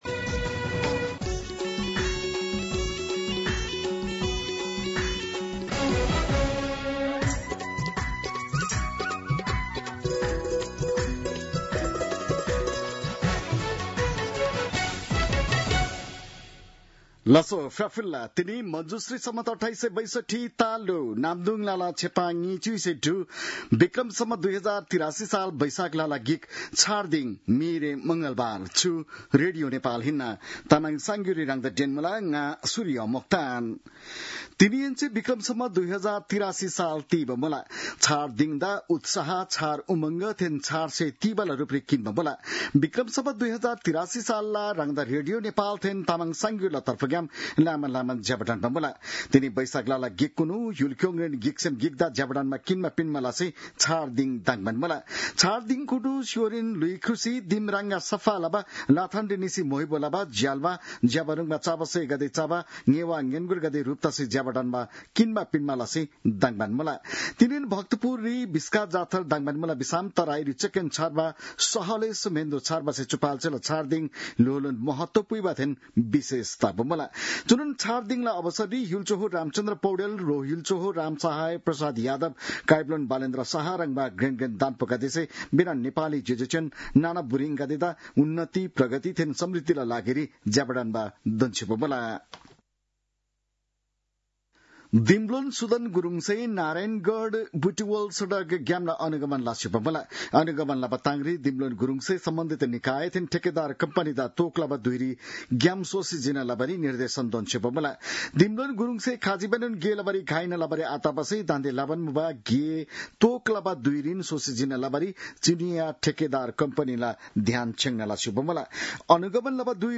तामाङ भाषाको समाचार : १ वैशाख , २०८३